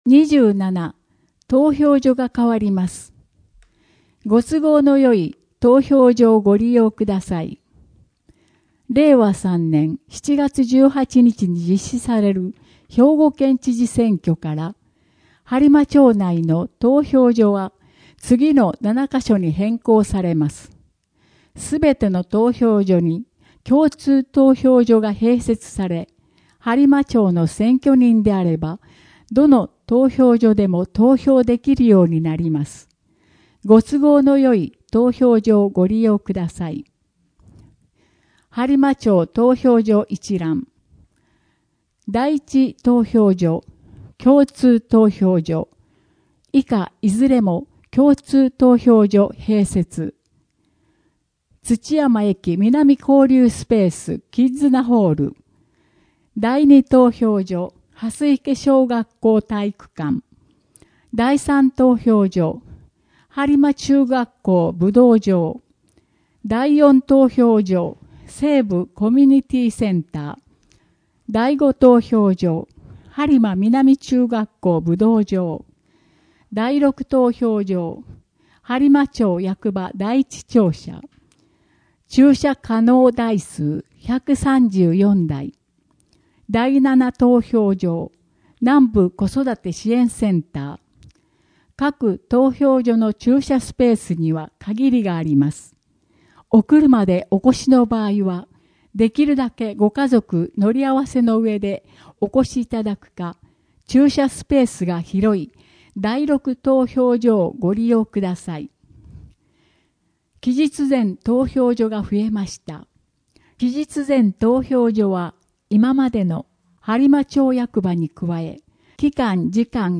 声の「広報はりま」5月号
声の「広報はりま」はボランティアグループ「のぎく」のご協力により作成されています。